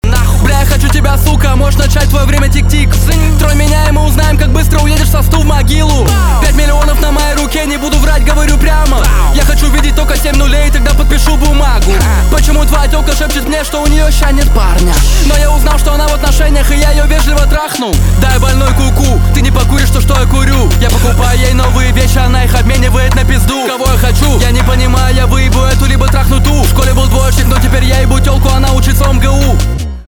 русский рэп
басы
жесткие